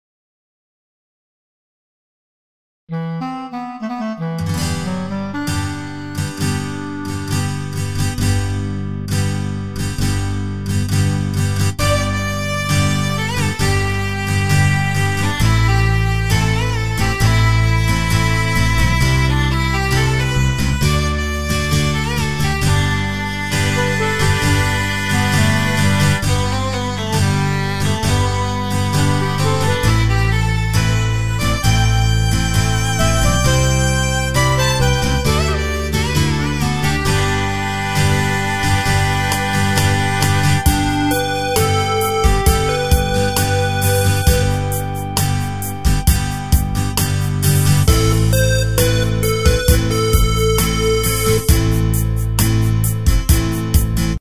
Tempo: 67 BPM.
MP3 with melody DEMO 30s (0.5 MB)zdarma